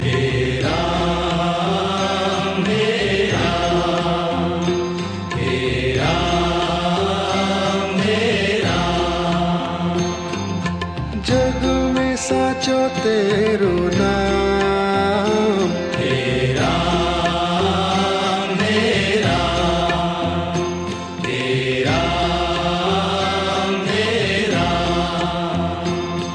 File Type : Bhajan mp3 ringtones